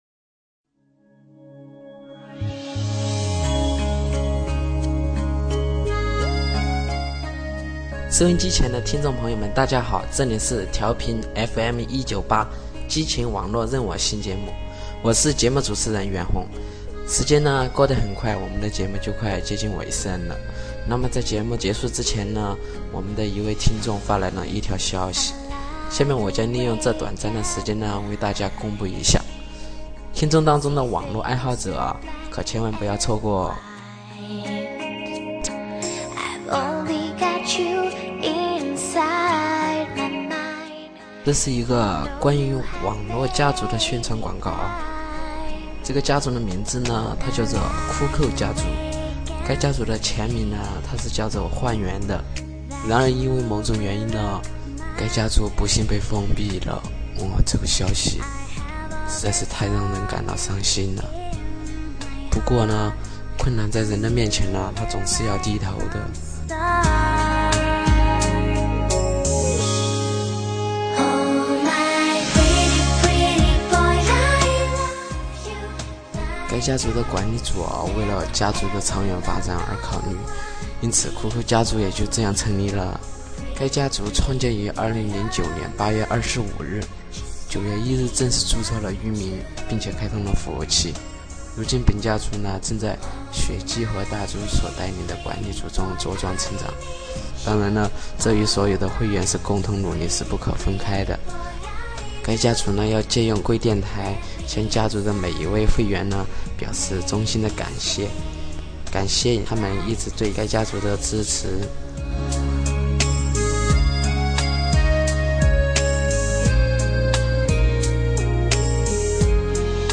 [MC喊麦]
本首MC欢迎大家欣赏